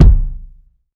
SouthSide Kick Edited (43).wav